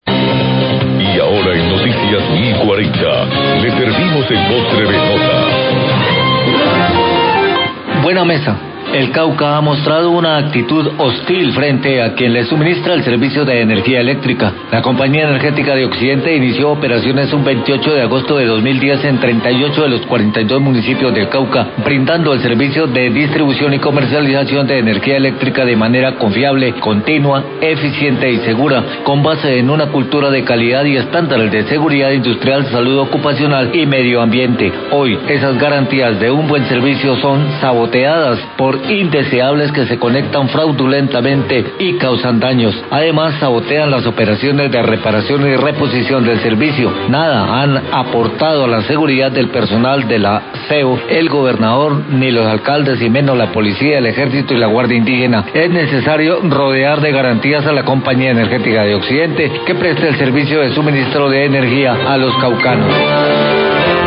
Radio
editorial